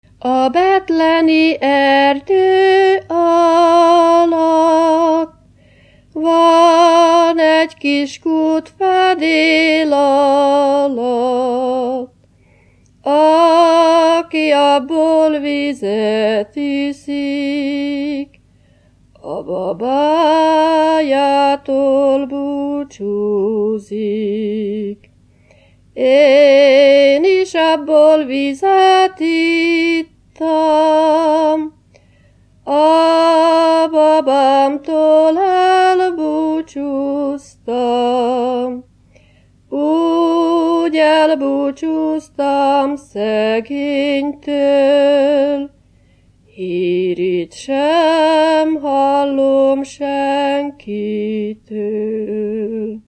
Erdély - Szolnok-Doboka vm. - Magyardécse
ének
Műfaj: Keserves
Stílus: 4. Sirató stílusú dallamok
Szótagszám: 8.8.8.8
Kadencia: 5 (b3) 4 1